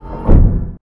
dockinggearretract.wav